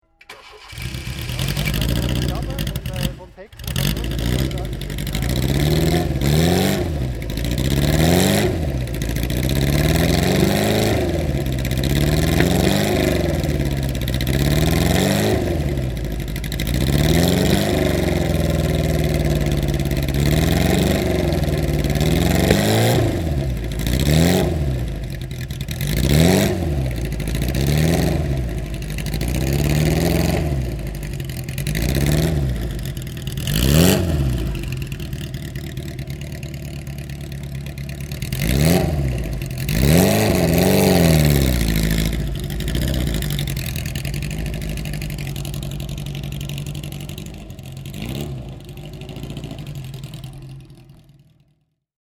Motorsounds und Tonaufnahmen zu Abarth Fahrzeugen (zufällige Auswahl)
Fiat-Abarth 1000 Bialbero (1960) - Starten und Leerlauf